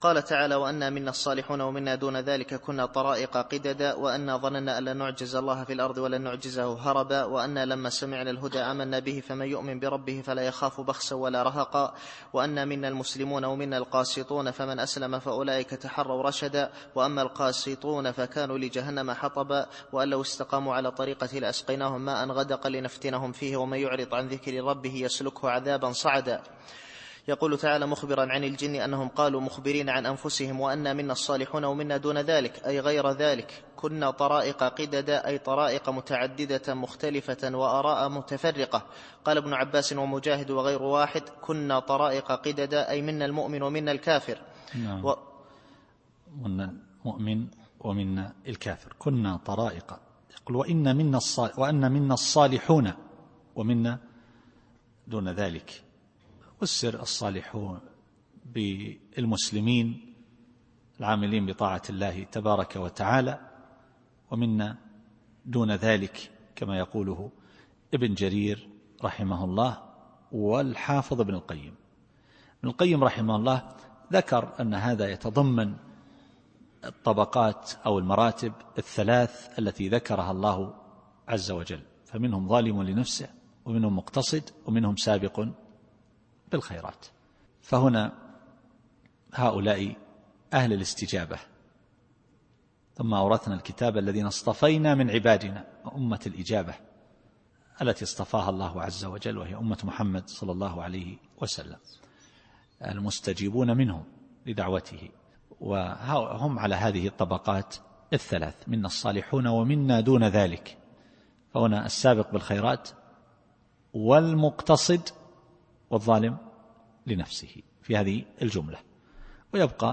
التفسير الصوتي [الجن / 11]